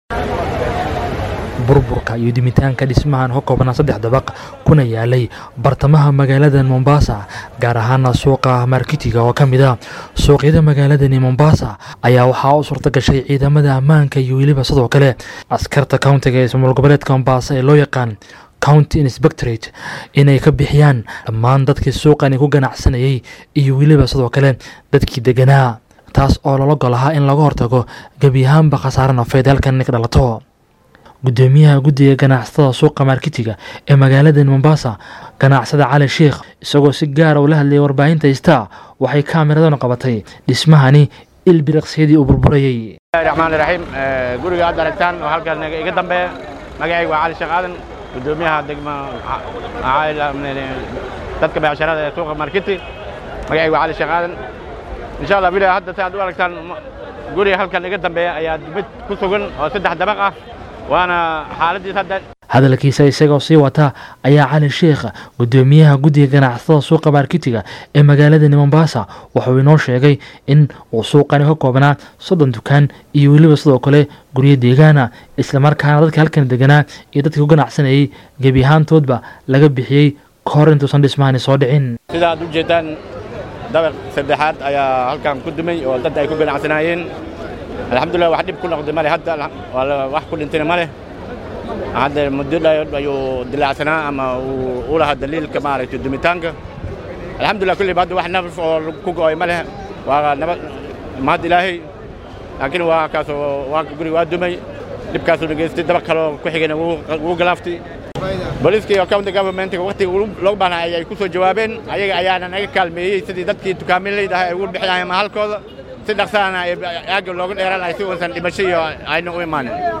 Wararka Kenya